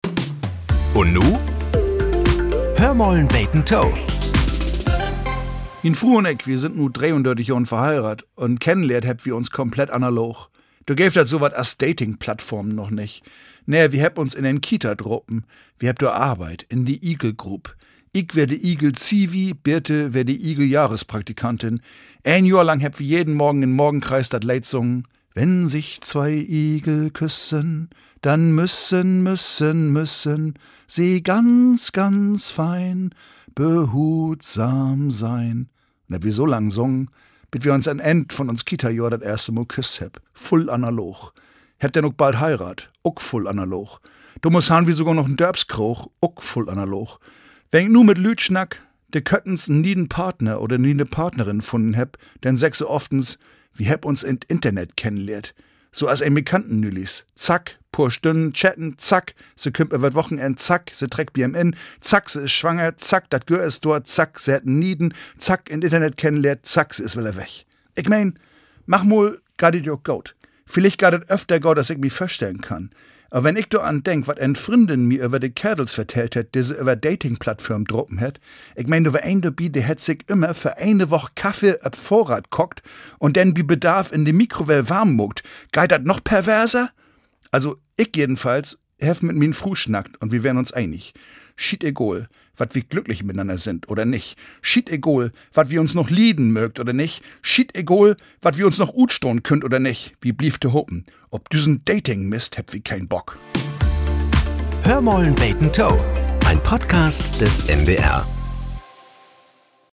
Nachrichten - 13.01.2025